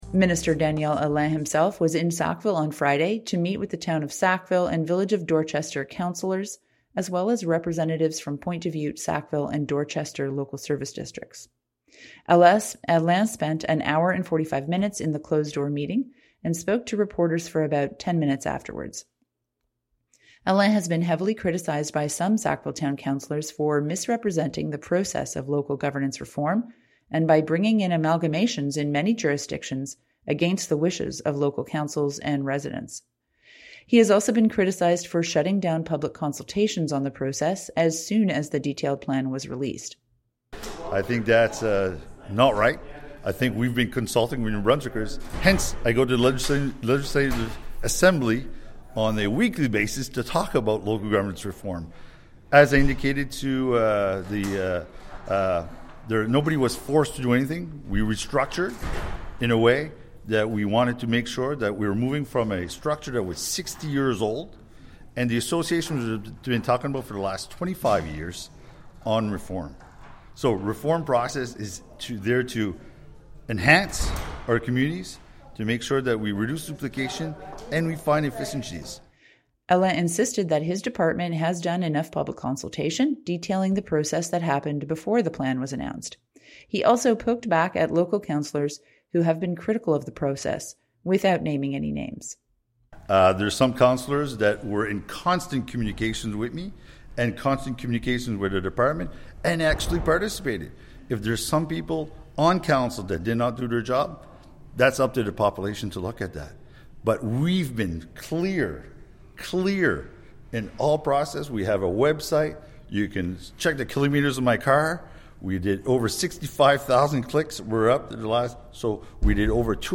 Allain spoke briefly with reporters afterwards, and defended the process of local government reform which will see the town of Sackville and village of Dorchester amalgamated into a much larger municipality to be named shortly.